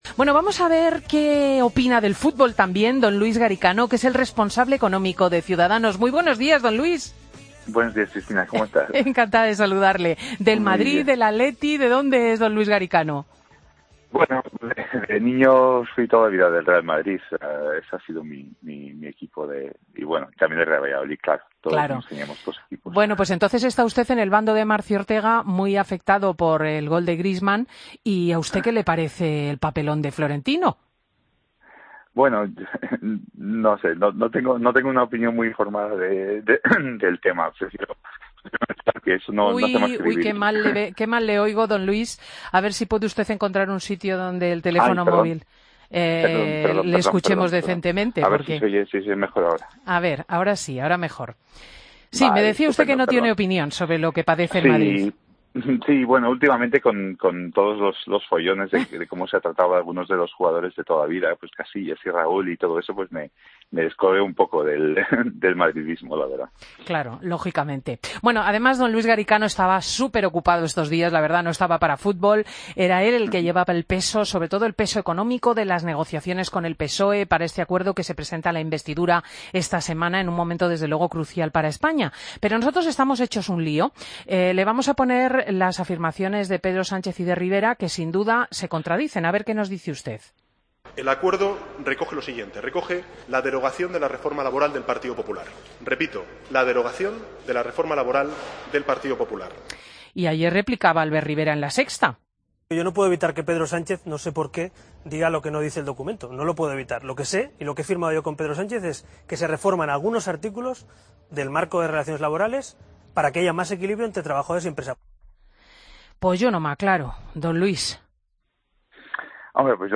Escucha la entrevista a Luis Garicano, Responsable económico de Ciudadanos, en Fin de Semana COPE